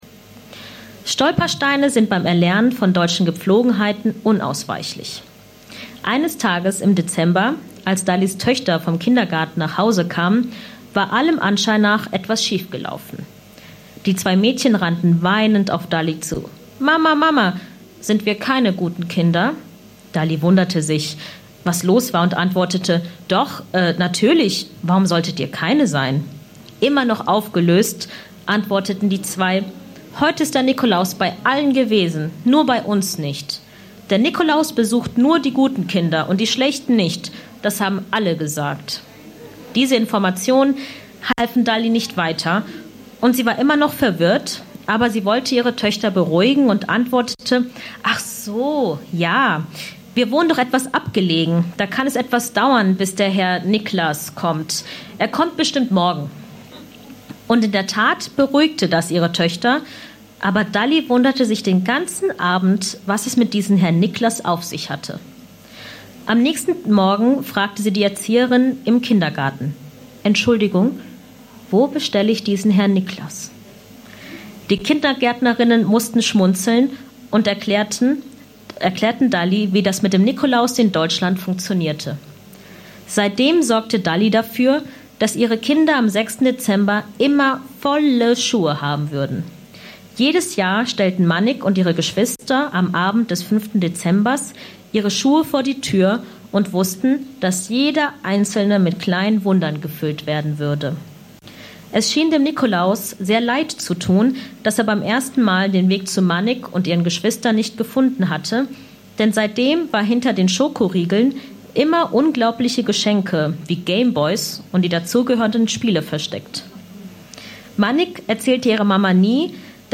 Lesung "Mama Superstar" Dein Browser kann kein HTML5-Audio.